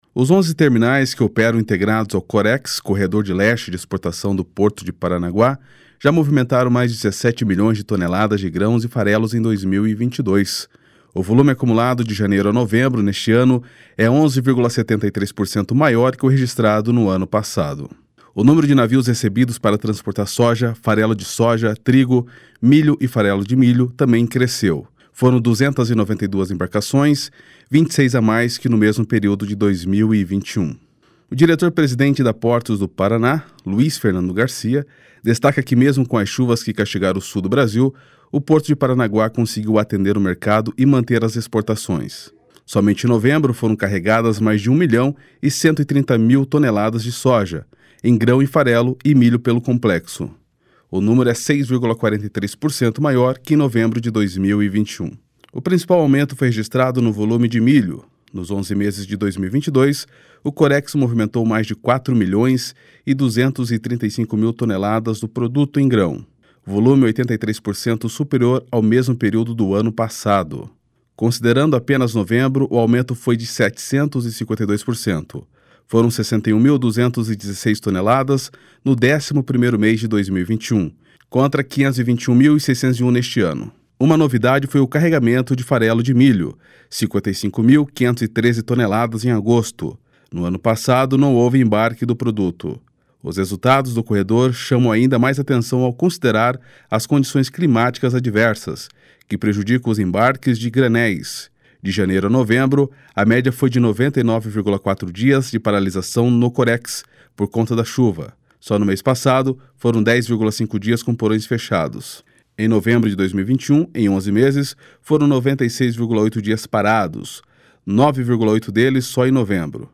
O diretor-presidente da Portos do Paraná, Luiz Fernando Garcia, destaca que mesmo com as chuvas que castigaram o Sul do Brasil, o Porto de Paranaguá conseguiu atender o mercado e manter as exportações.